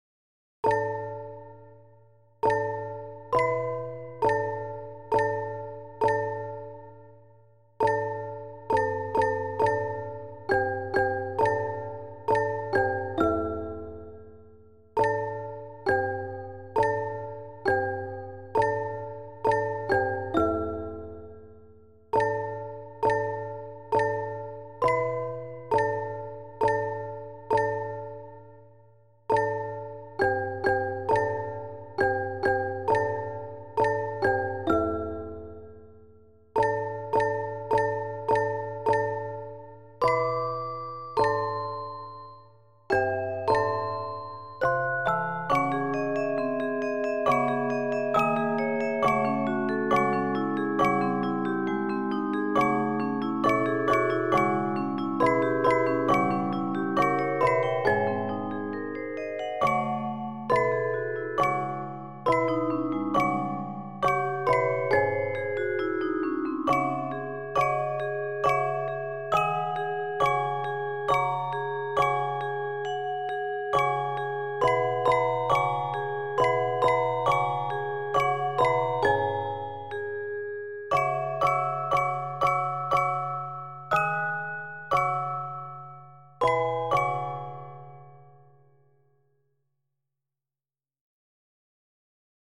children's song Japan